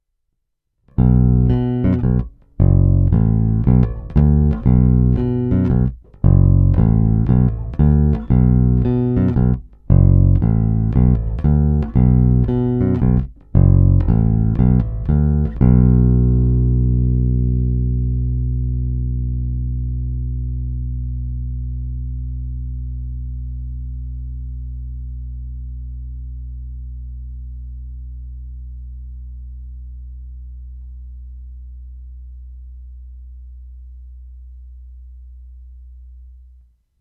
Není-li uvedeno jinak, následující nahrávky jsou provedeny rovnou do zvukové karty a s plně otevřenou tónovou clonou a s korekcemi na nule. Nahrávky jsou jen normalizovány, jinak ponechány bez úprav. Hráno nad použitým snímačem, v případě obou hráno mezi nimi. Na baskytaře jsou nataženy poniklované roundwound pětačtyřicítky Elixir Nanoweb v dobrém stavu.
Snímač u krku